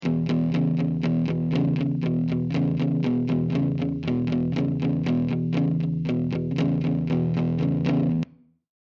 Apesar do riff ser baseado em colcheias, usar a palhetada alternada não se mostrava tão apelativa como palhetadas só para baixo, e isto resulta num som mais agressivo e autentico.
Neste caso estamos no acorde de Mi.
Ritmo Rock
Ouça o áudio em baixo, no caso está a ser utilizado o palm muting, uma técnica que veremos mais à frente, que consiste em encostar a lateral da sua mão direita na ponte da guitarra.